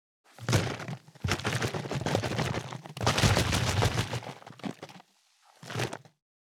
346ペットボトル,ワインボトルを振る,水の音,ジュースを振る,シャカシャカ,カシャカシャ,チャプチャプ,ポチャポチャ,シャバシャバ,チャプン,ドボドボ,
ペットボトル